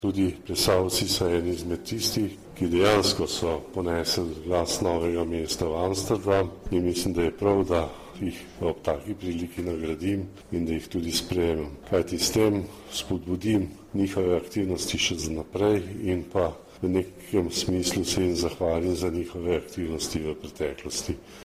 Župan Alojzij Muhič o sprejemu plesalcev